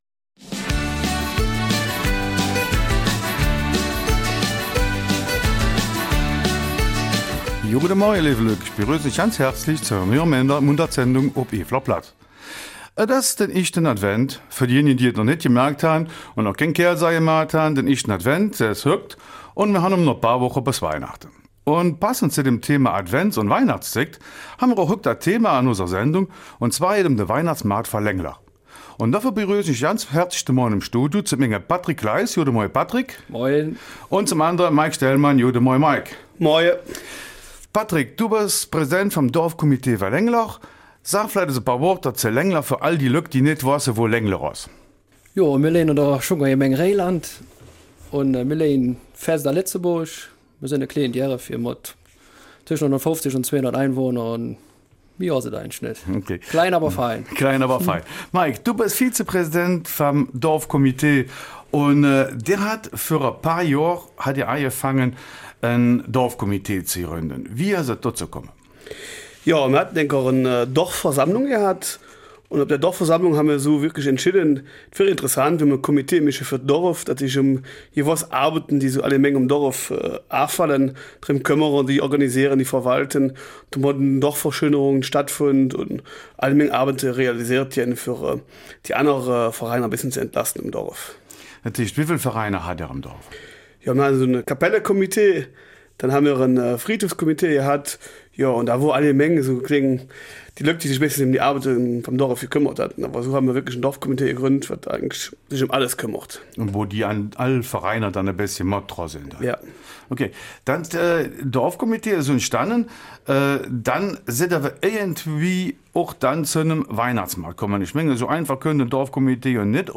Eifeler Mundart: Weihnachtsmarkt in Lengeler